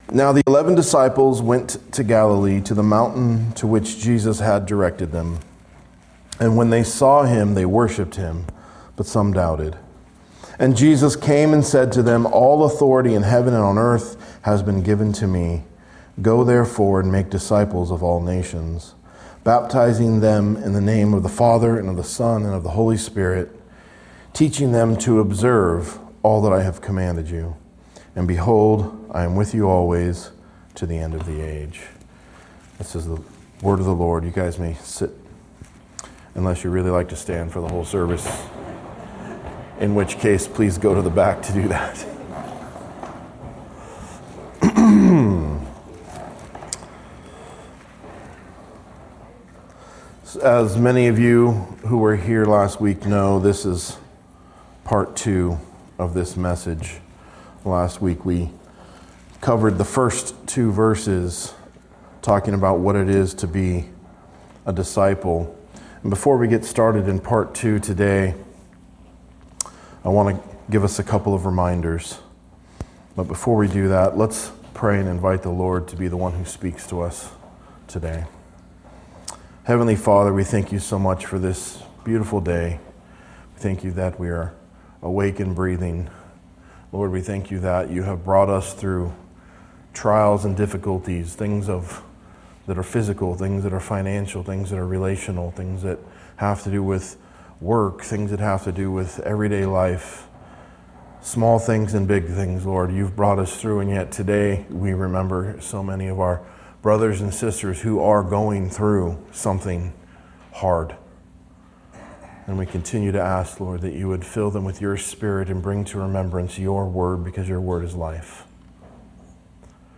Expository teaching of Matthew 28:16-20